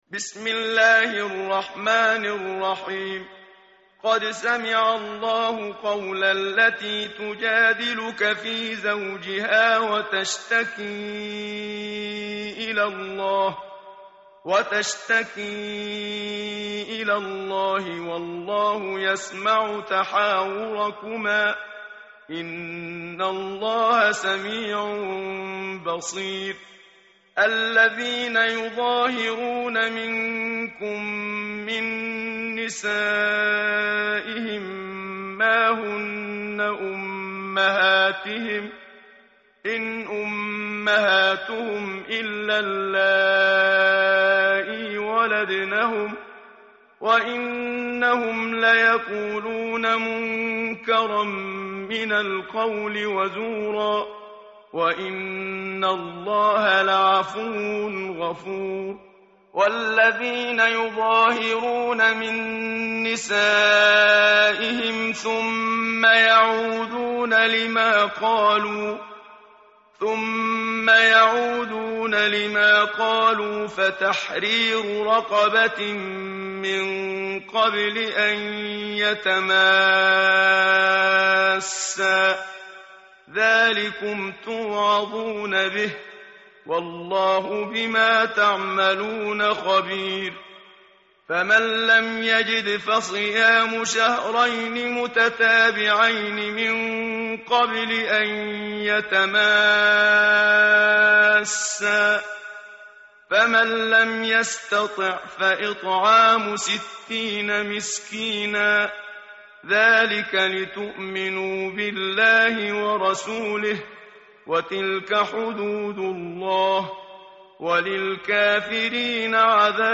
tartil_menshavi_page_542.mp3